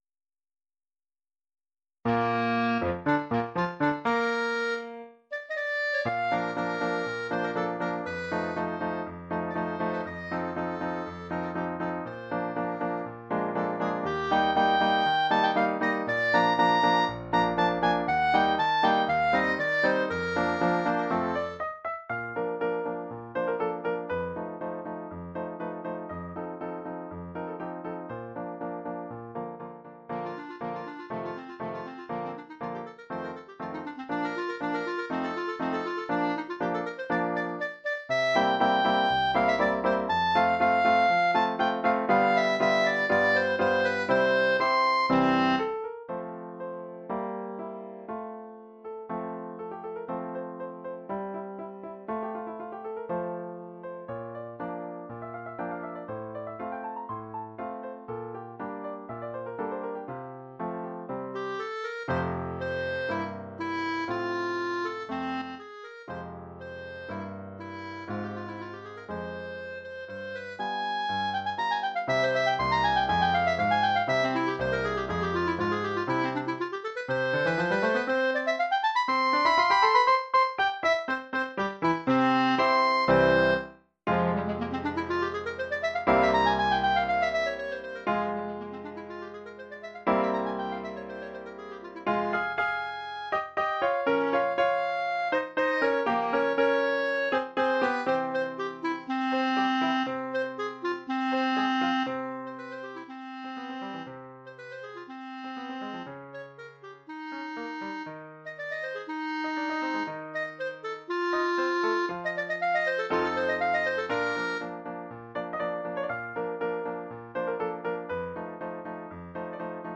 Sonatine en trois mouvements
pour clarinette et piano.